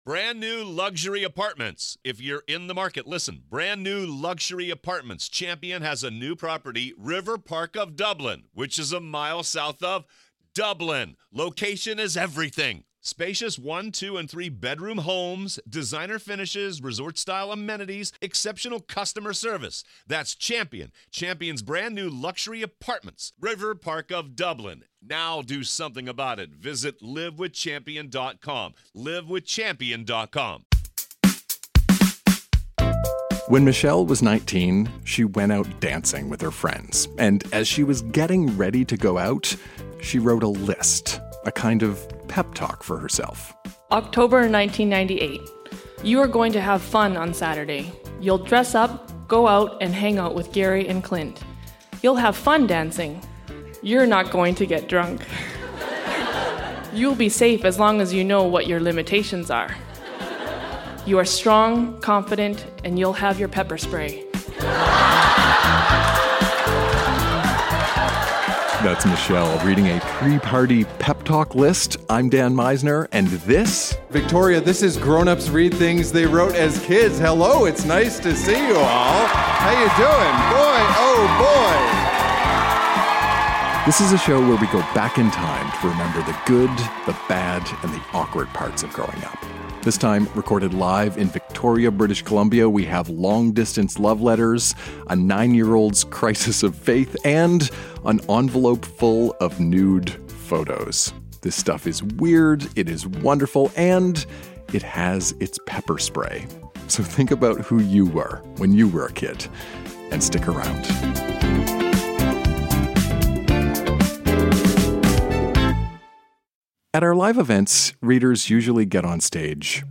Recorded live at the Victoria Event Centre in Victoria, BC.